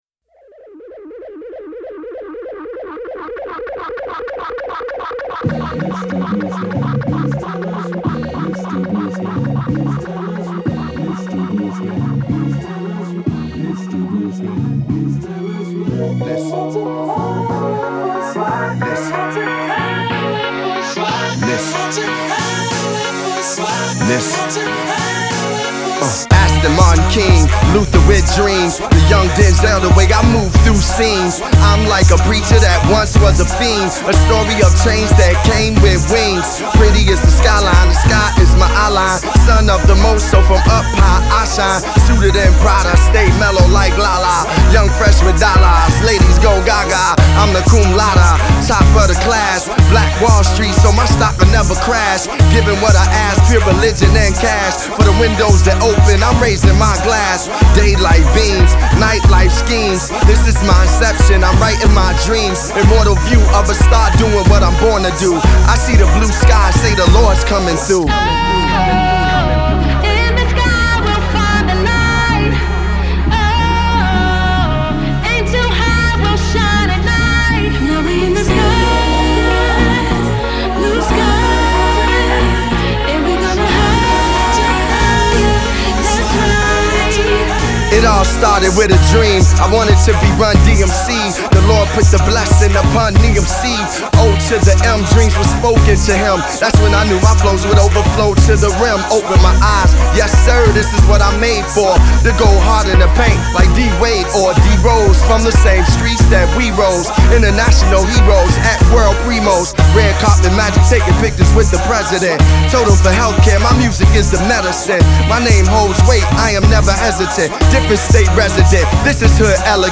Nice production on this one.